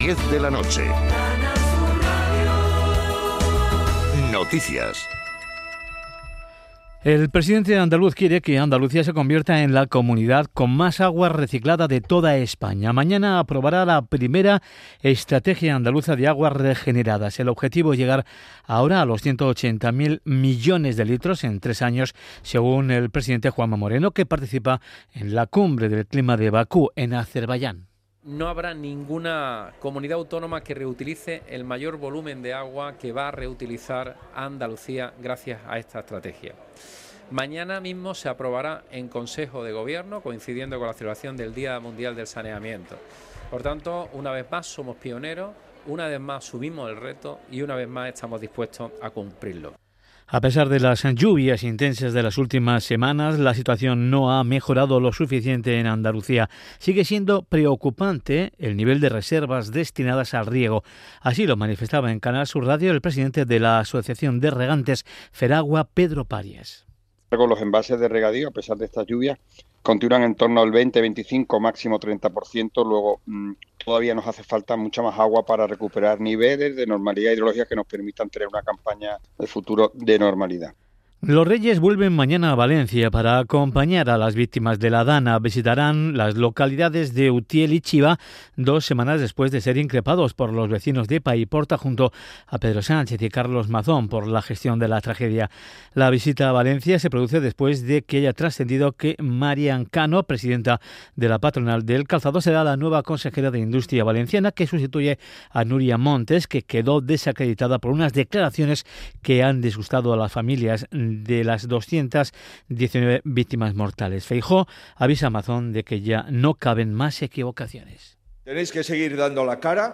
Canal Sur Radio y Radio Andalucía Información son las únicas cadenas que transmiten para toda Andalucía y el mundo el Concurso de Agrupaciones del Carnaval de Cádiz de manera íntegra.